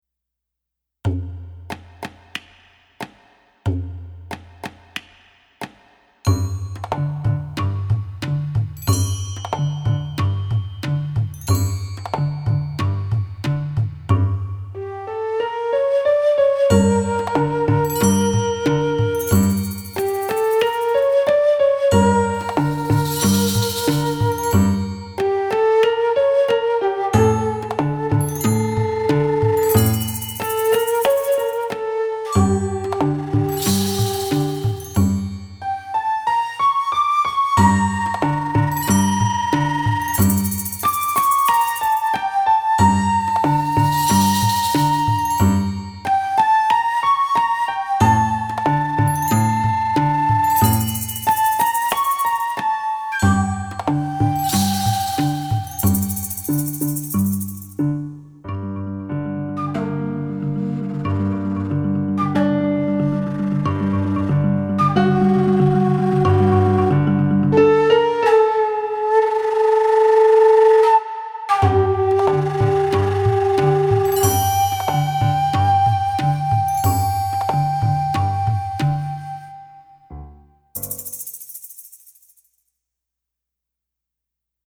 Performance-tempo samples
Newly-orchestrated background accompaniments
wide variety of musical textures and sounds
Then at performance tempo with the piano part muted